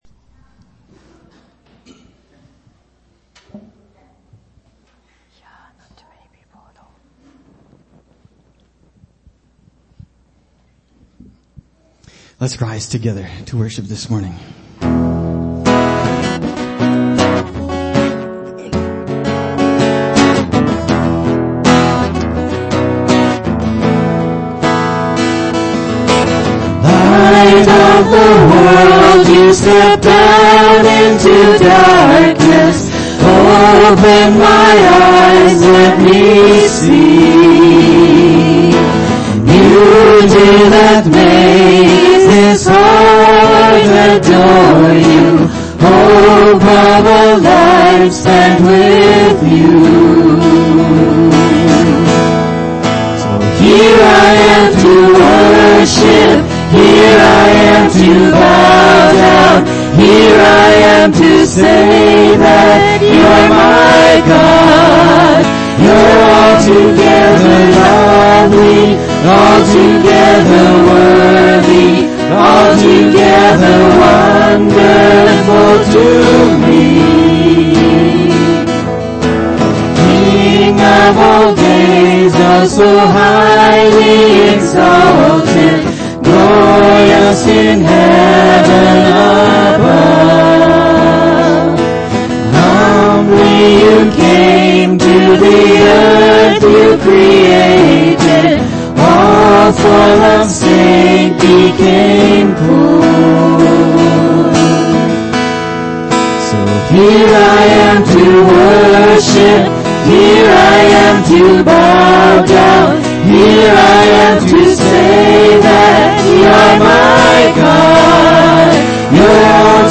February-7-2016-sermon.mp3